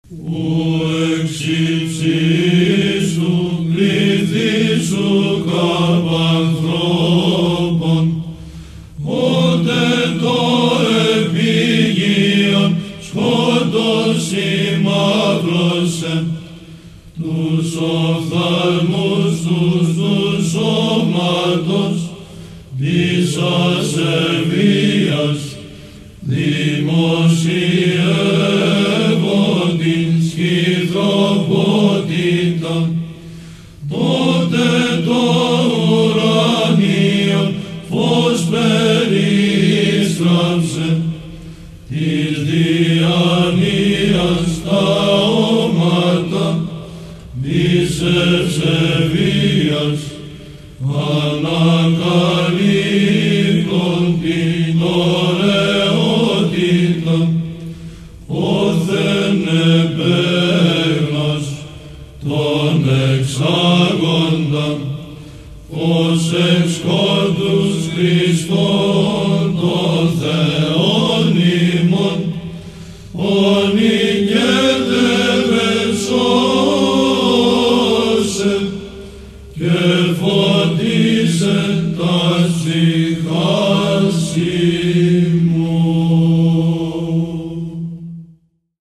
Ήχος δ'